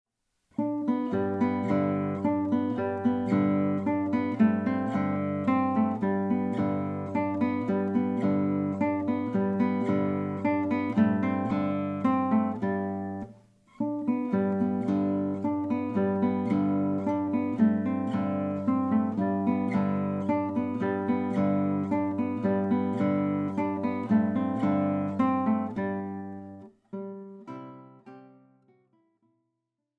Gitarrenmusik aus Wien von 1800-1856
Besetzung: Gitarre